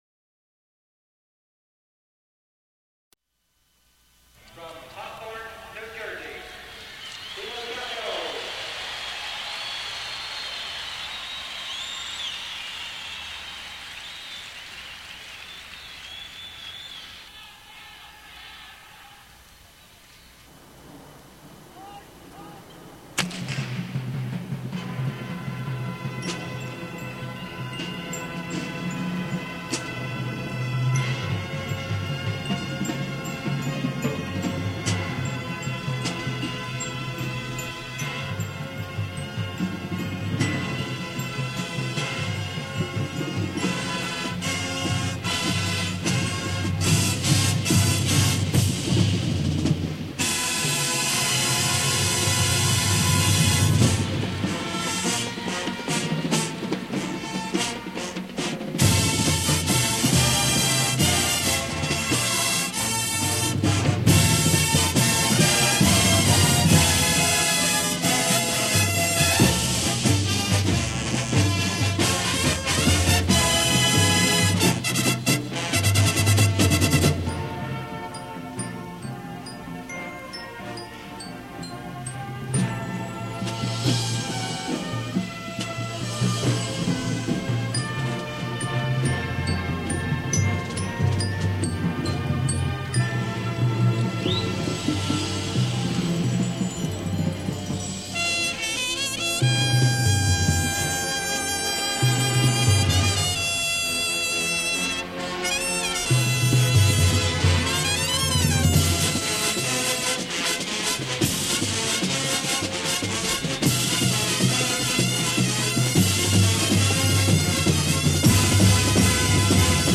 GREAT corps with one of the all time best snare lines and a smoking hornline.